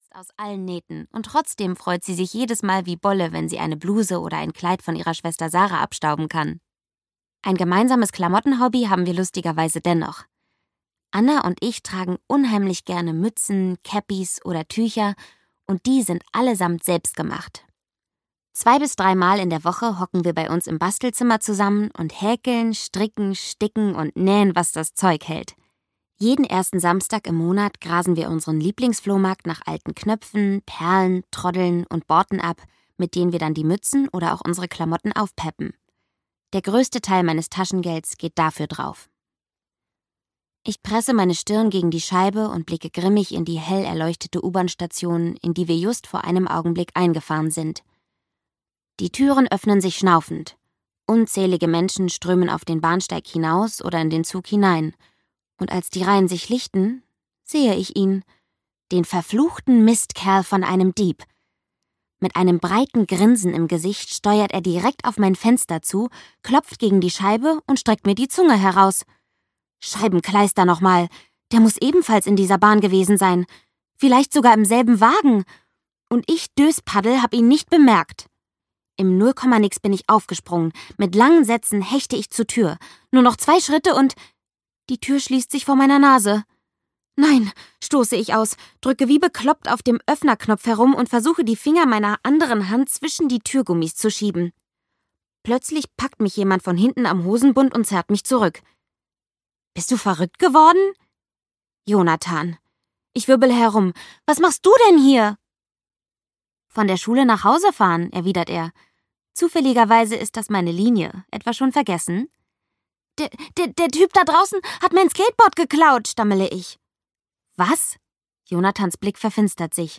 Emely – total vernetzt! (Lesegören zum Hören) - Patricia Schröder - Hörbuch